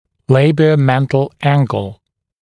[ˌleɪbɪə(u)’mentl ‘æŋgl][ˌлэйбио(у)’мэнтл ‘энгл]губоподбородочный угол